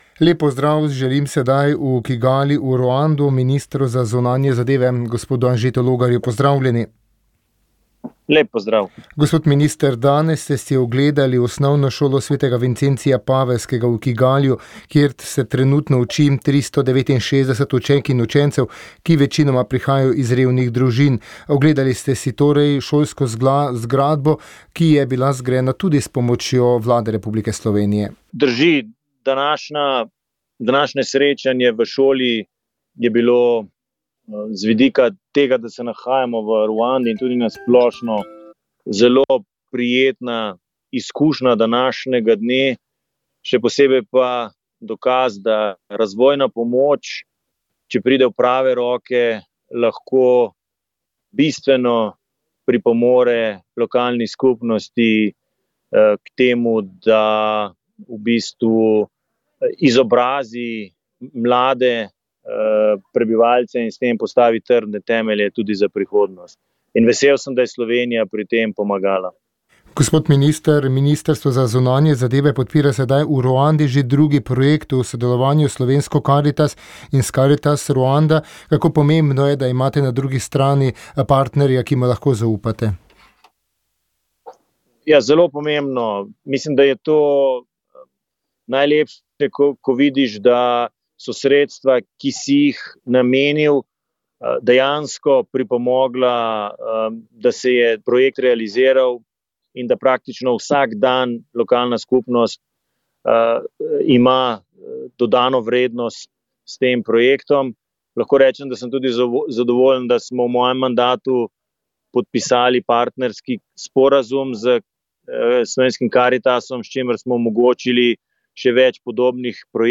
Izjava ministra za zunanje zadeve dr. Anžeta Logarja
minister_logar_kigali_pogovor.mp3